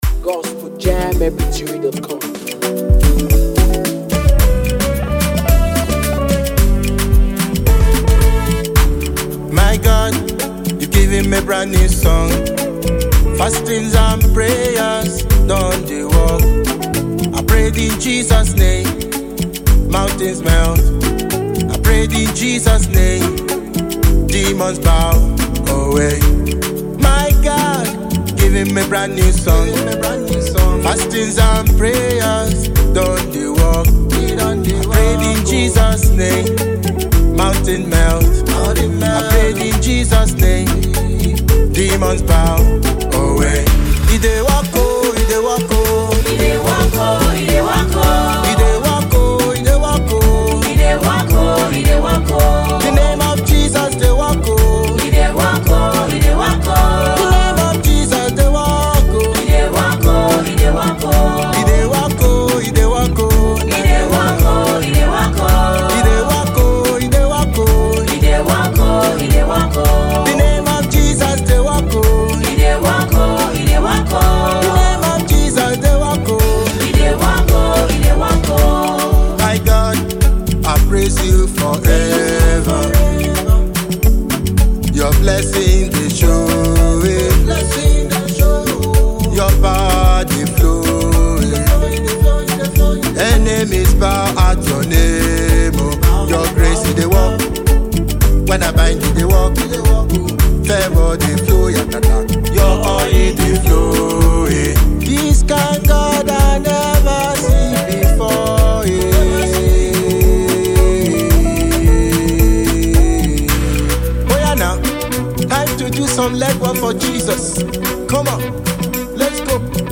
gospel praise song
With energetic rhythms and uplifting lyrics